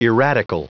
Prononciation du mot erratical en anglais (fichier audio)
Prononciation du mot : erratical